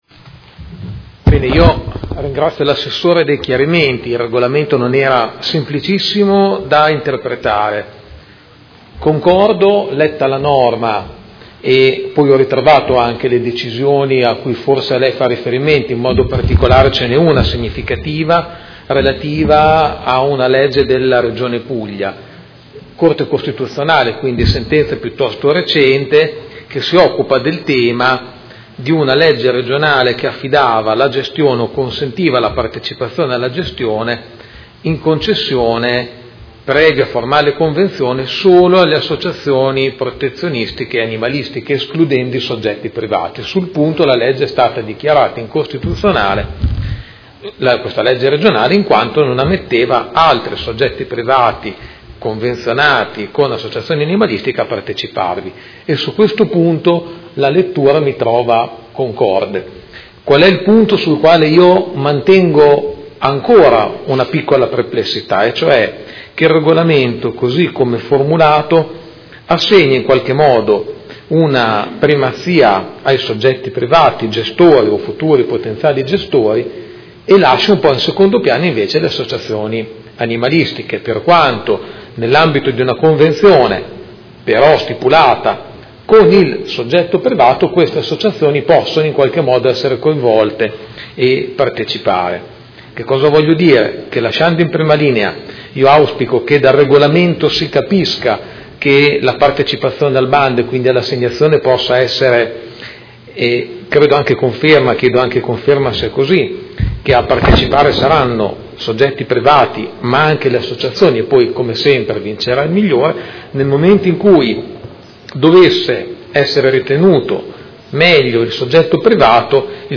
Giuseppe Pellacani — Sito Audio Consiglio Comunale
Seduta del 14/12/2017 Dichiarazione di voto.